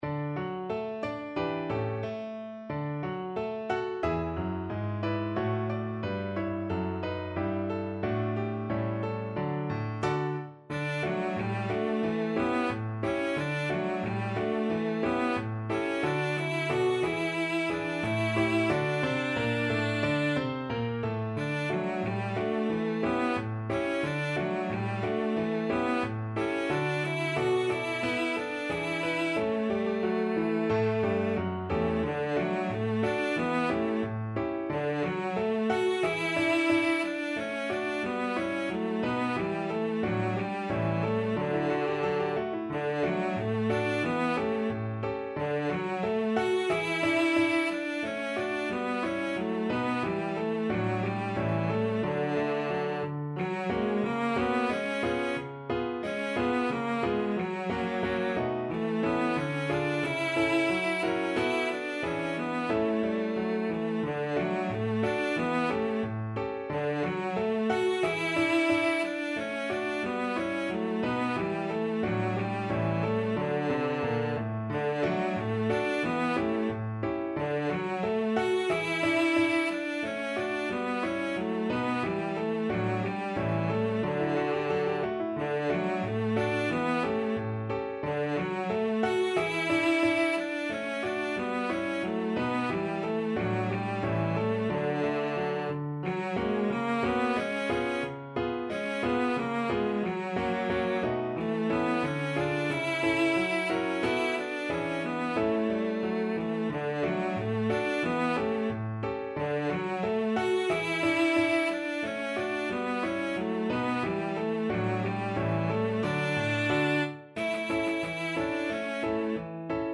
Cello version
2/2 (View more 2/2 Music)
=90 Fast and cheerful
Pop (View more Pop Cello Music)